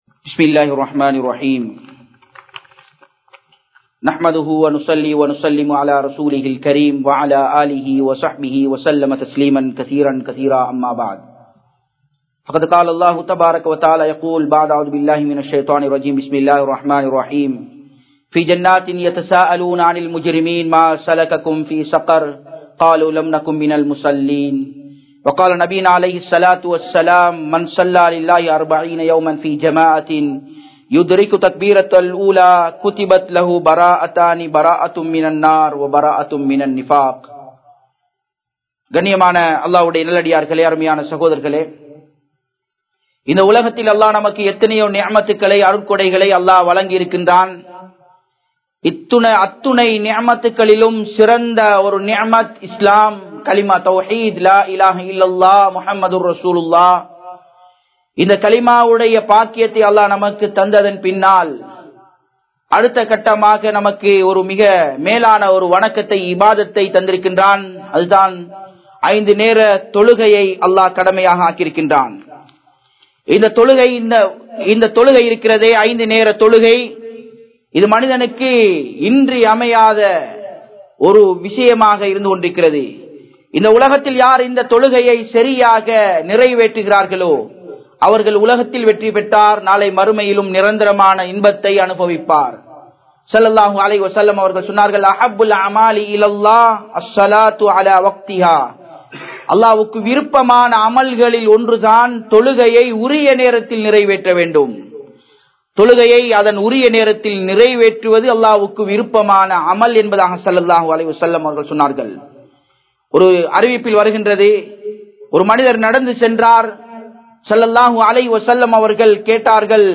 Tholuhaiyai Vittu Vidatheerhal (தொழுகையை விட்டு விடாதீர்கள்) | Audio Bayans | All Ceylon Muslim Youth Community | Addalaichenai